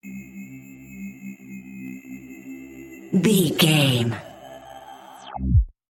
Dark Disappear Scifi
Sound Effects
Atonal
magical
mystical